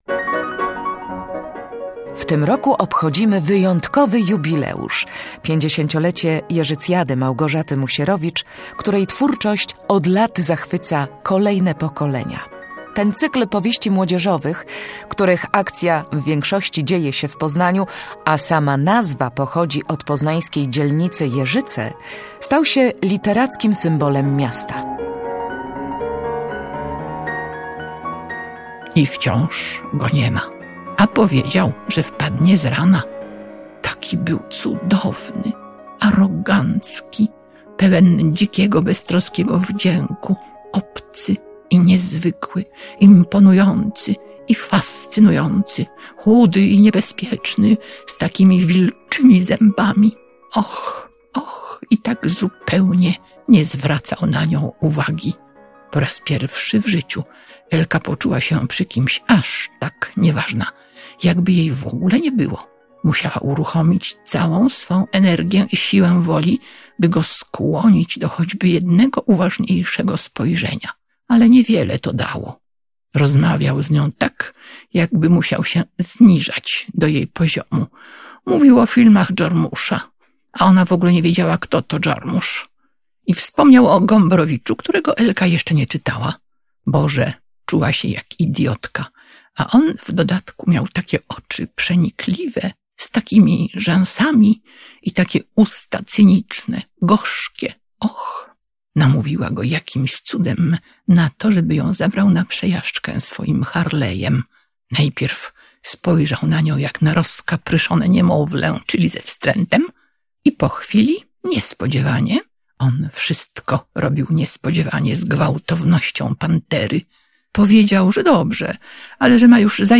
W naszym cyklu fragmenty "Noelki" czytała sama autorka.